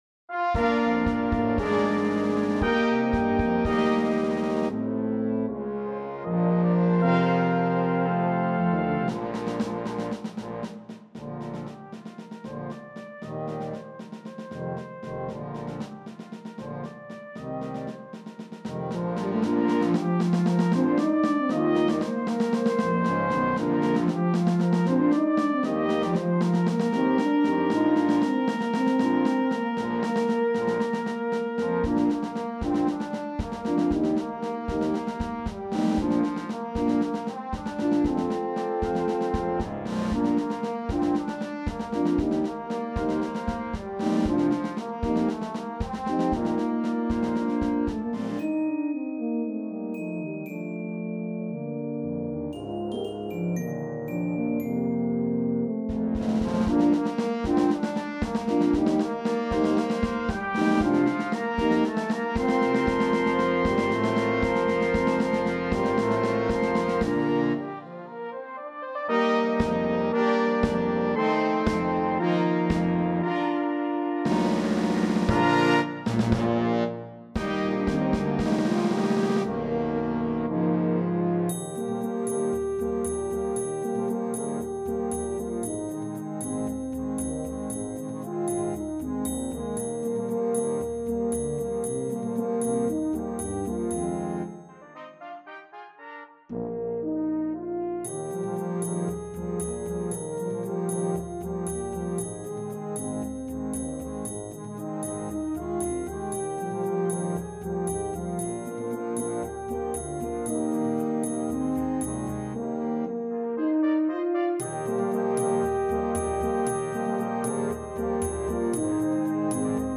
(March) This uplifting march
presented in a calypso style